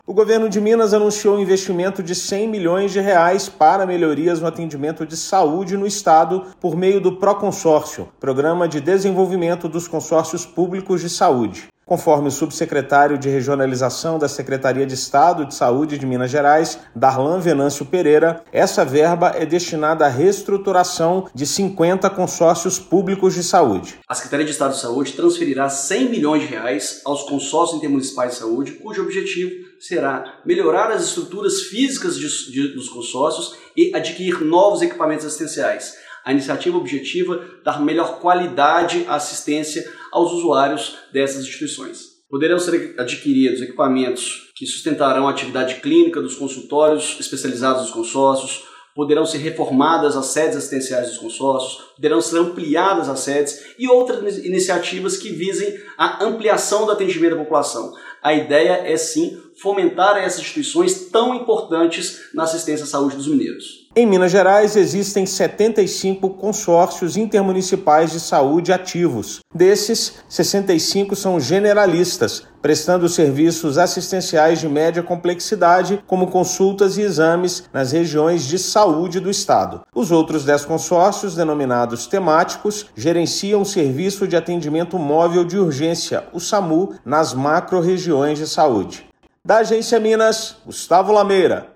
Secretaria de Saúde investe nos consórcios para garantir a qualidade dos serviços nas diversas regiões. Ouça matéria de rádio.